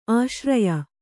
♪ āśraya